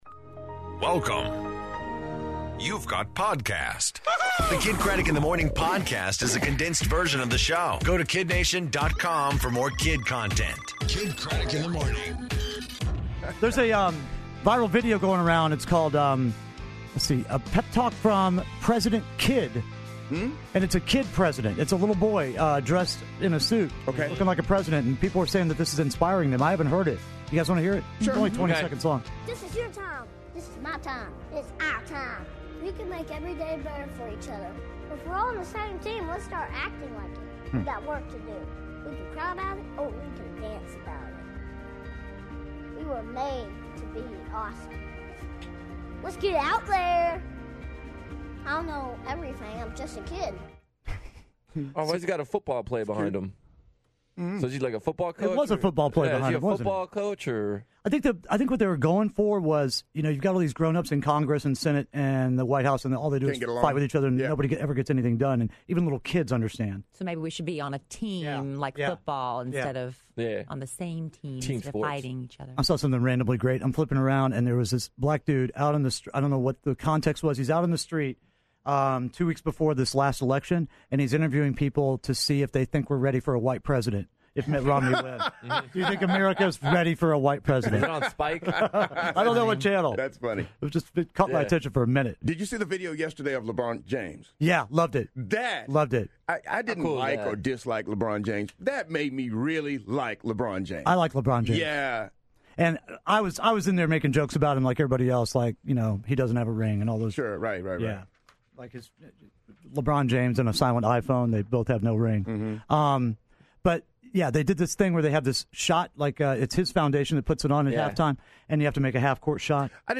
How to eat grapefruit, Kidd's junk is on fire, and Josh Henderson from Dallas is in studio.